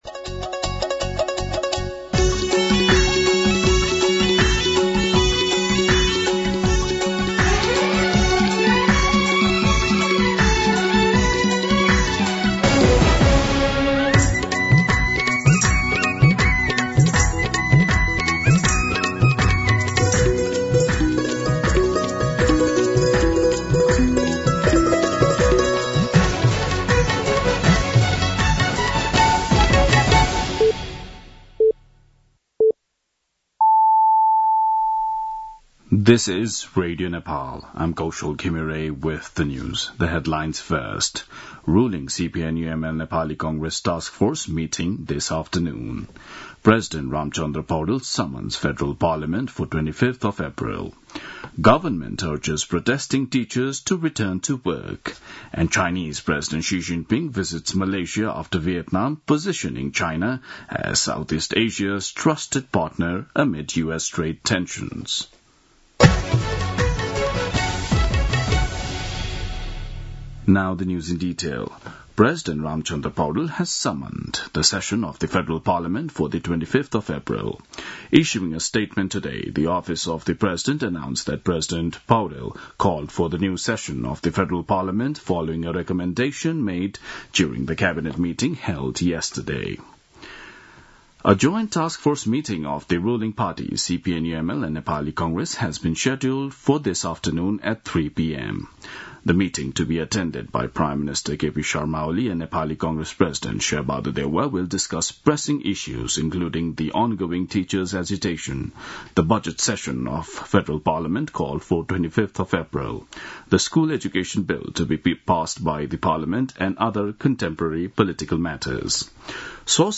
दिउँसो २ बजेको अङ्ग्रेजी समाचार : ३ वैशाख , २०८२
2-am-english-news-.mp3